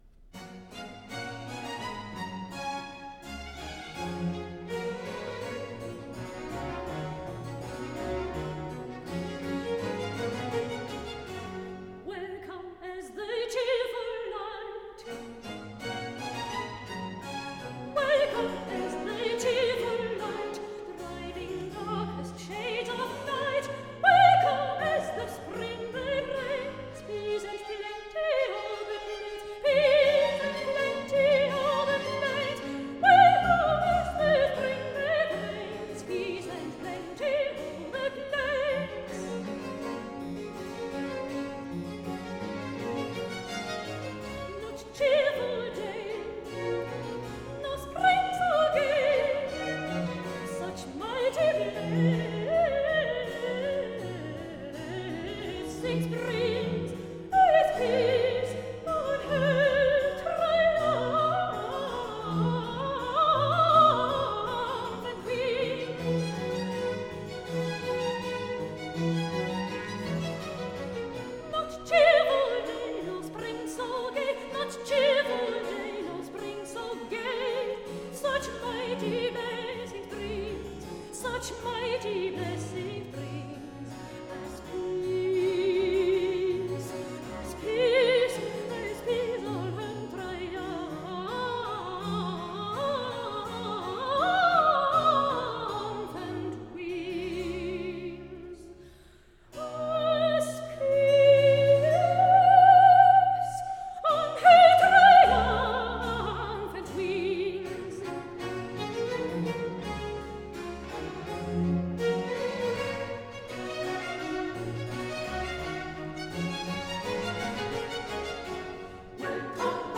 Air
Chorus